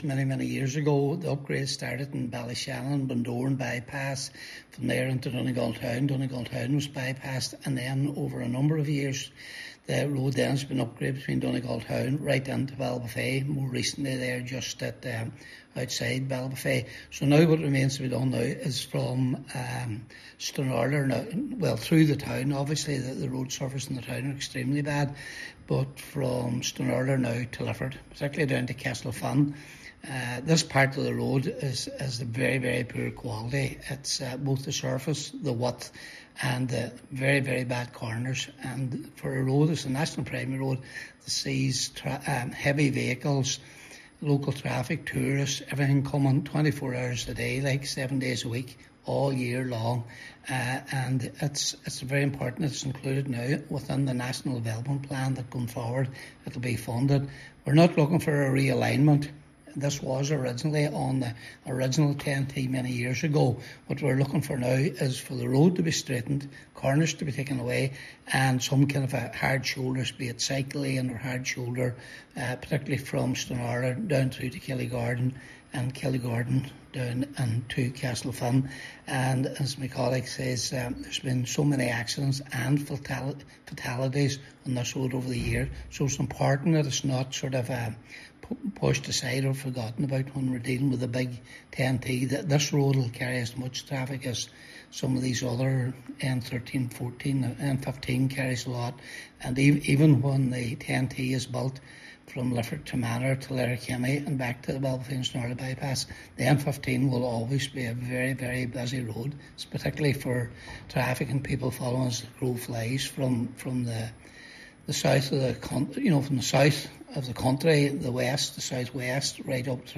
Councillor McGowan says road safety needs to be a priority on the road, given the high volume of traffic that uses it daily: